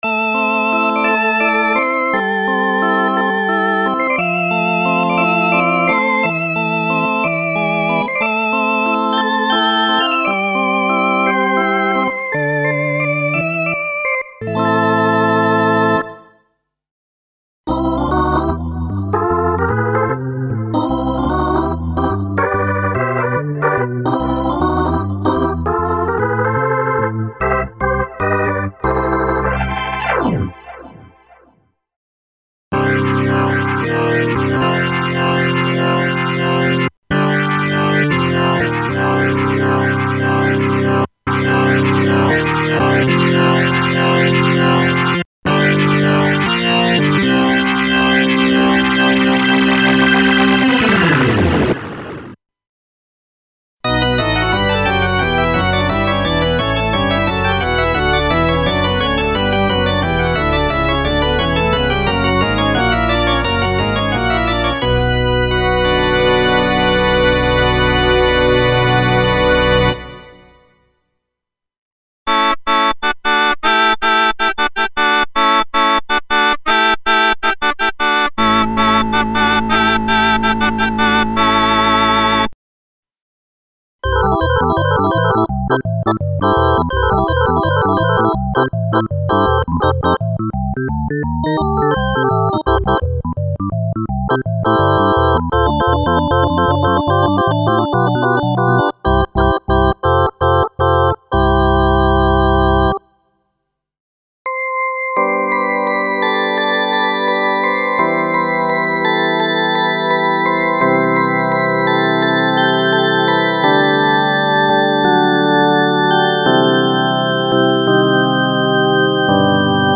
Ultimate collection of electric organ emulations based on Kurzweil PC3´s internal ROM samples (organ, organ drawbars and organ partials) and KB3 Mode.
This soundware collection contains the vintage electric organs including filtered, specially modulated and distorted organ sounds for various music styles.
• Single effects: (Delay, Chorus, Flanger, Phaser, Distortion)